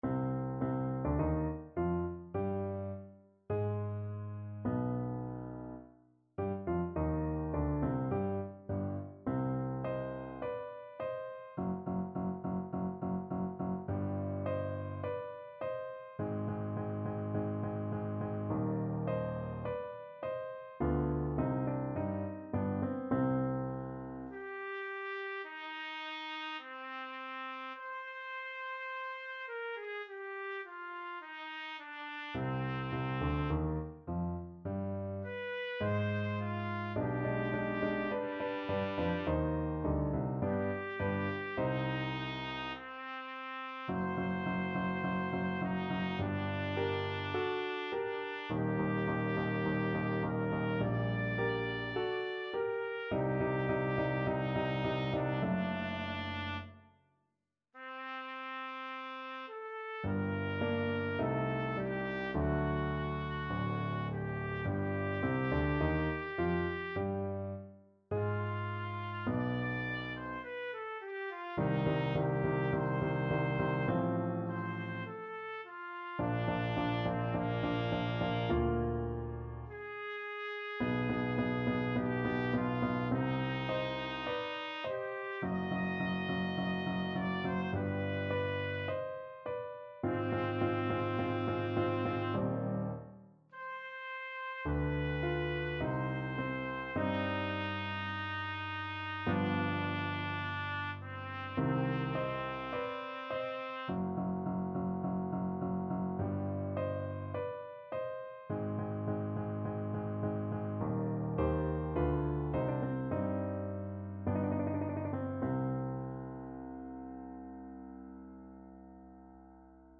Trumpet version
4/4 (View more 4/4 Music)
~ = 52 Recit: Andante
Classical (View more Classical Trumpet Music)